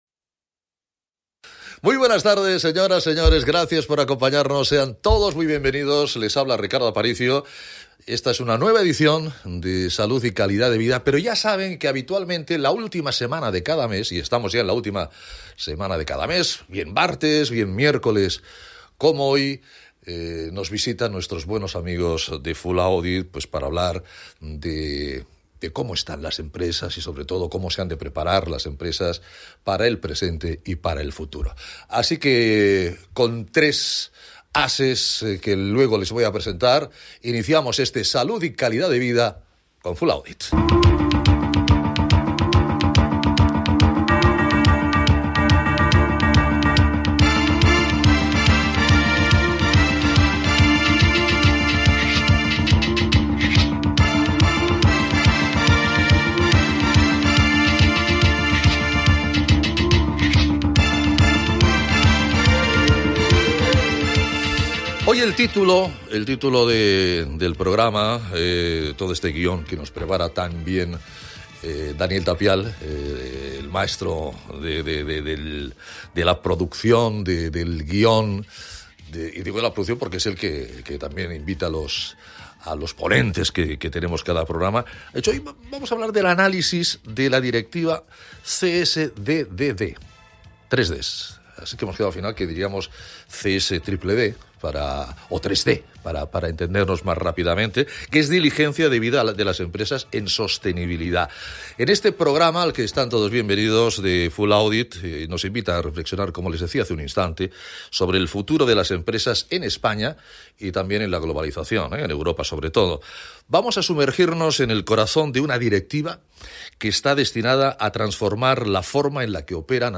El pasado 26 de marzo, de 19 h a 20 h, tuvimos un nuevo programa de radio en el que reflexionamos sobre el futuro de las empresas en España y en toda Europa: nos sumergimos en el corazón de una directiva que está destinada a transformar la forma en que las empresas operan, la Directiva CSDDD.